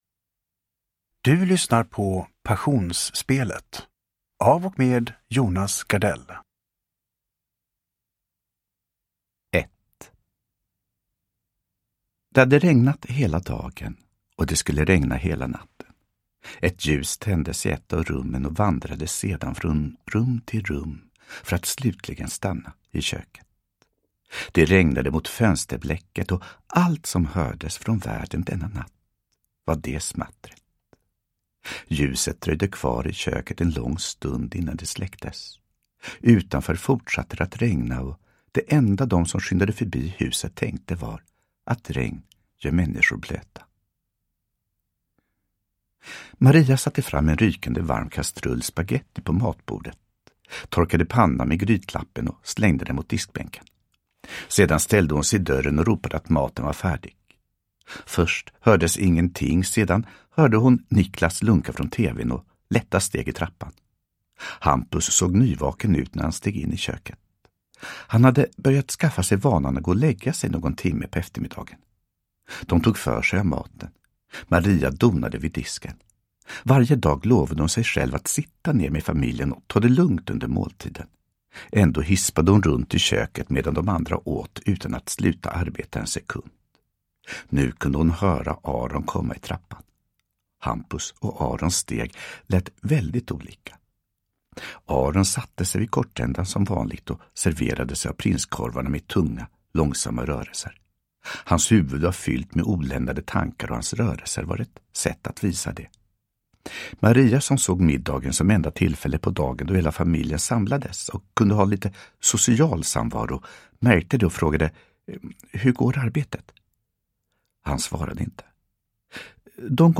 Passionsspelet – Ljudbok – Laddas ner
Uppläsare: Jonas Gardell